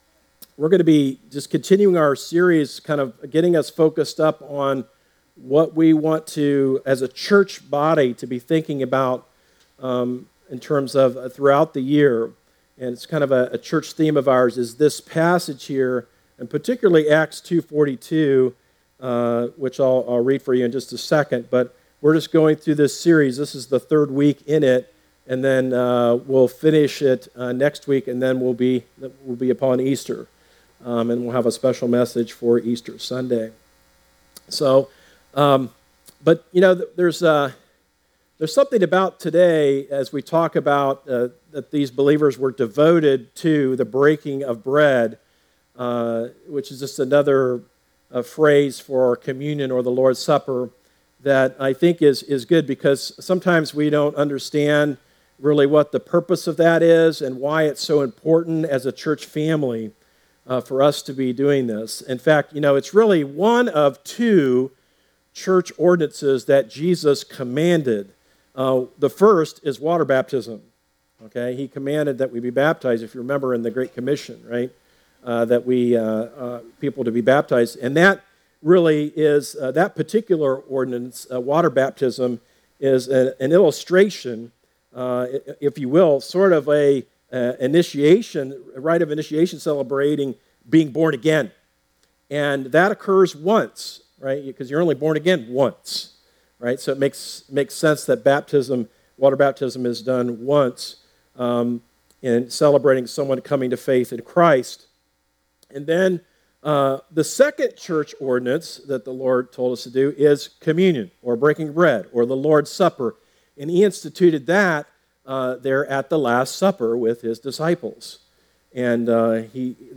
Message
Join us for a time of worship and Bible teaching. We will be studying why the believers were devoted to the breaking of bread. The apostle Paul also offers us some insight into the importance of this time of remembrance in the life of the church.